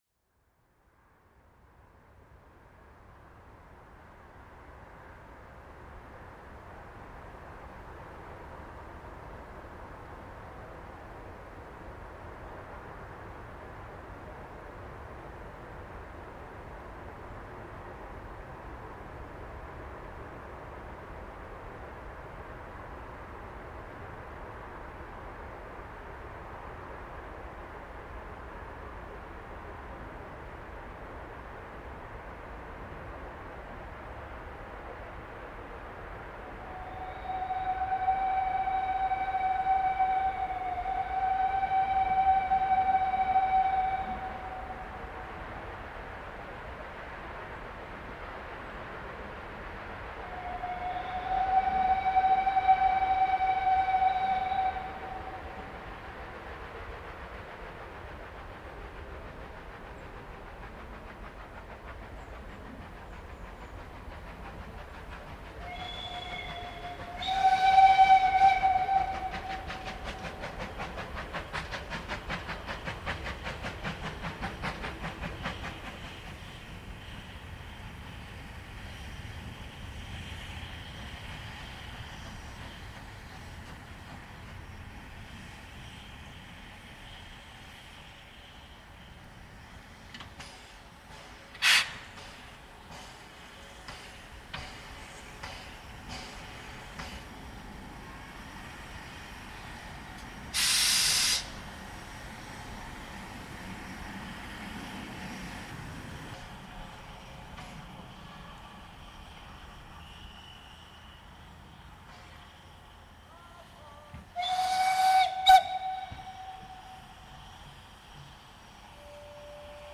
Soundaufnahmen von Dampflokomotiven, nur hochwertige, nur vollständige und alle zum Downloaden in guter (stereo)-Qualität